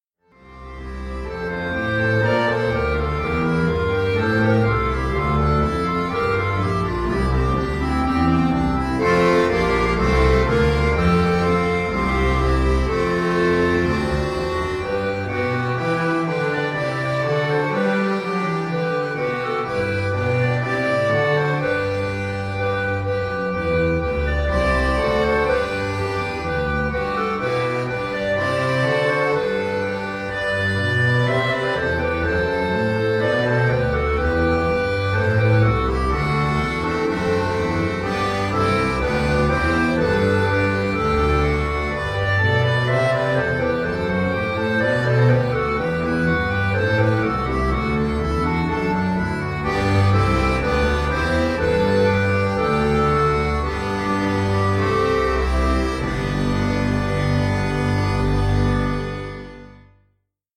Traditionelles Weihnachtslied
Weihnachtslied